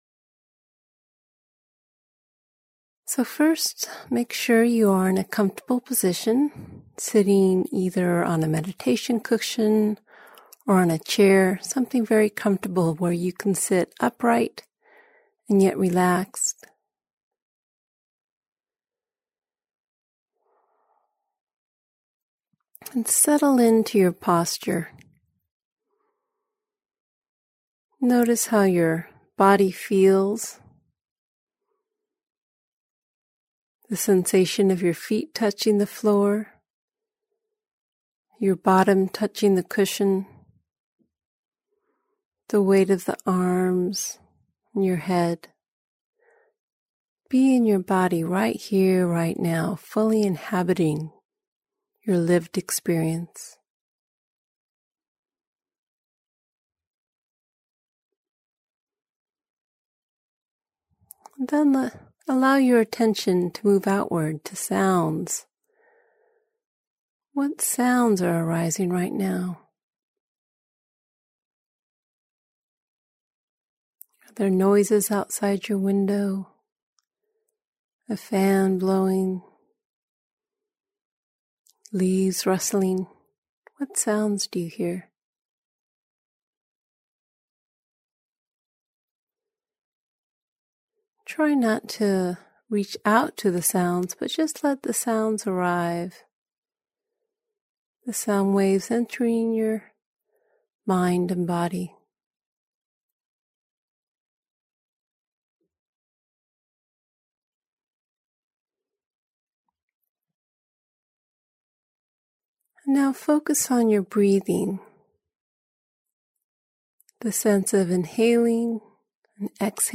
Self-Compassion/Loving-Kindness Meditation